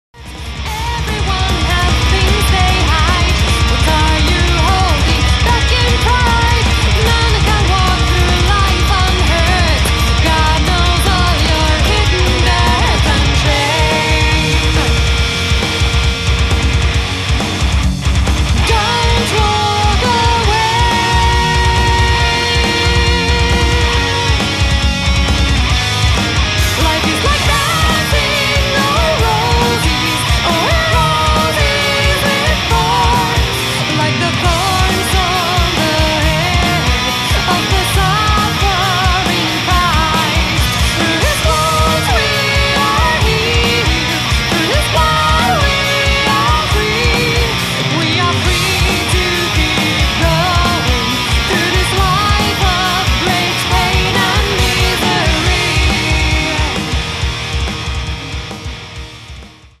Kuvaus:Kahden hevibändin kitaristi.
Millaista musiikkia bändinne soittaa?: Metallia
Onko laulajanne: Nainen / tyttö